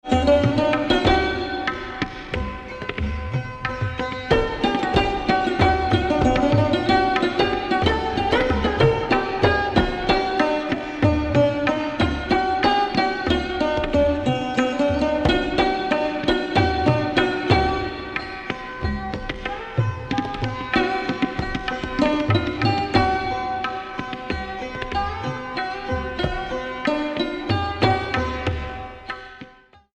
S-R-g-M-P-d-n-S
Aroha: SRgMPdnS
Avroh: SndPMgRS
Pakad: variable, e.g. MPdndP; gMPMgRS